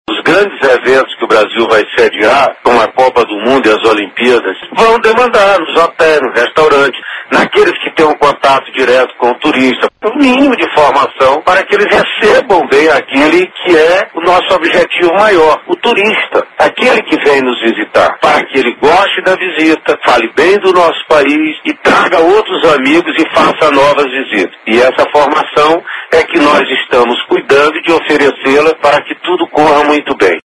aqui para ouvir declaração do ministro Gastão Vieira sobre a importância da qualificação prrofissional voltada ao turismo.